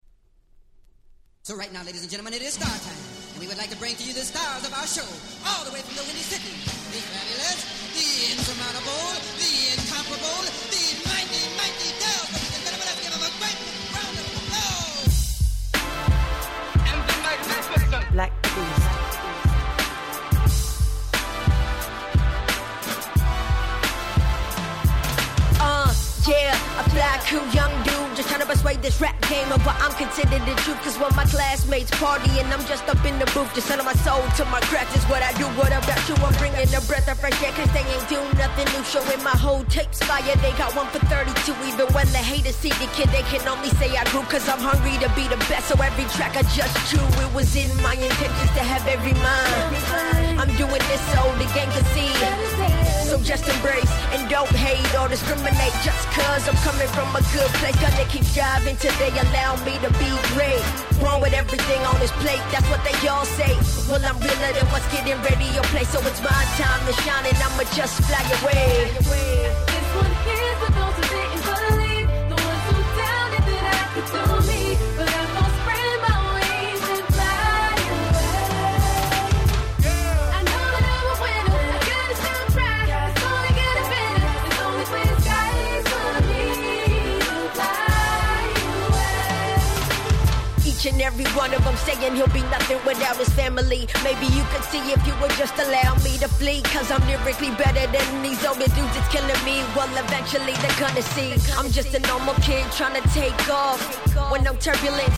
09' Nice Hip Hop !!